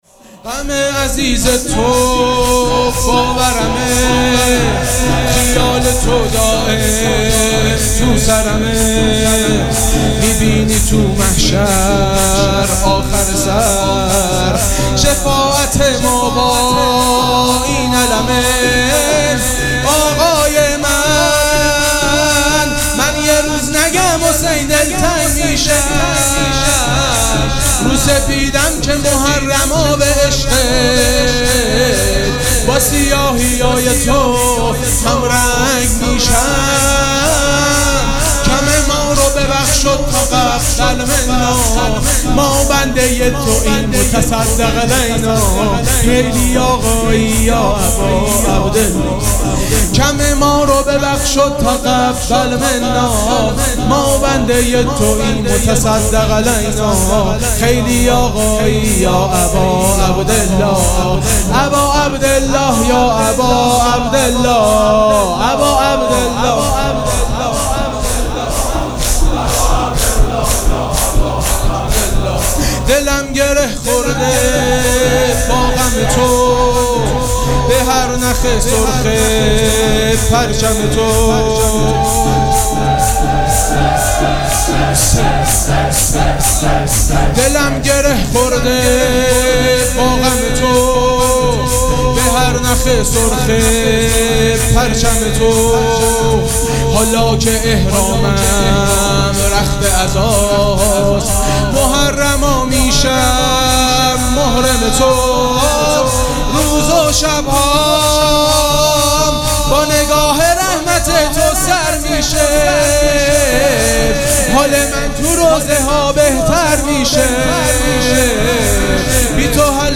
شور
مداح